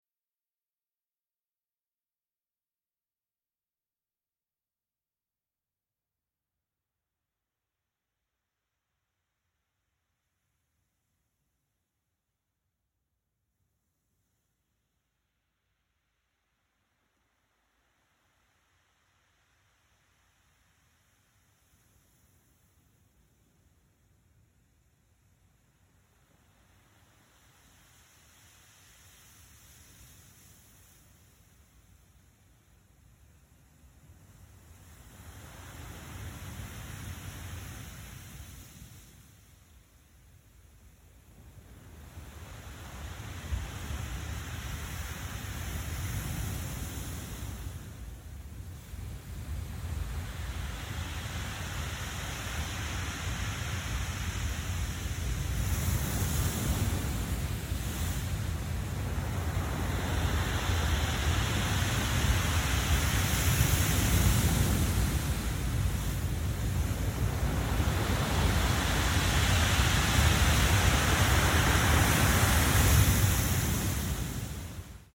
Recorded at Big Orange Sheep, Brooklyn